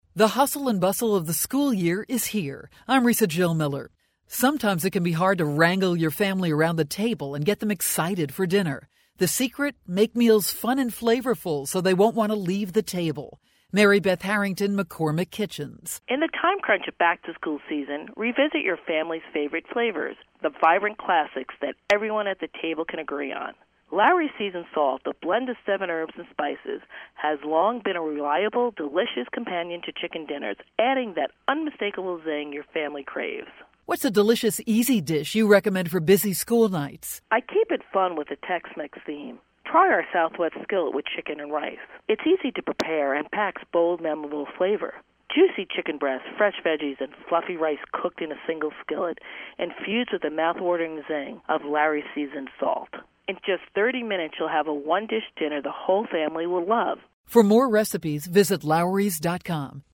September 6, 2012Posted in: Audio News Release